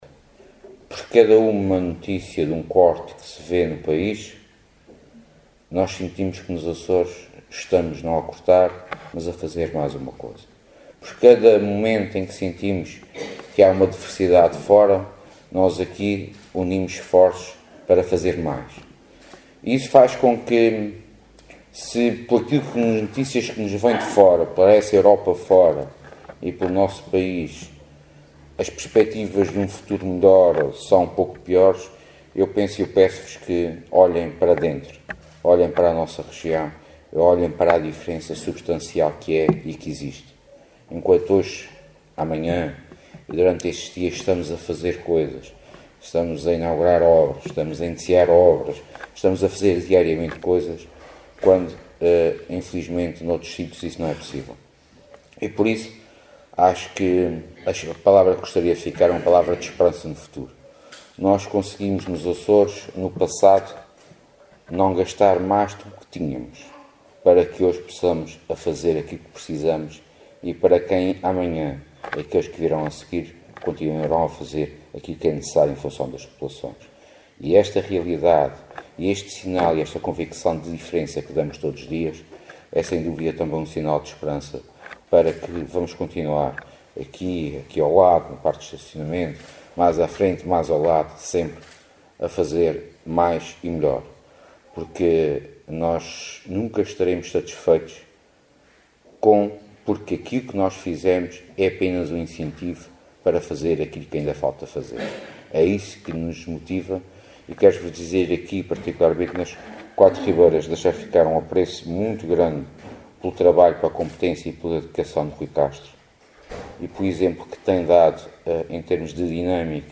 “Por cada notícia que ouvimos das perspetivas por essa Europa fora, de mais um corte no País, nós sentimos que, nos Açores, estamos, não a cortar, mas a fazer mais uma coisa”, afirmou Sérgio Ávila, na intervenção que proferiu sábado à noite na inauguração das obras de remodelação da sede da Sociedade Recreativa de Santa Beatriz, na ilha Terceira.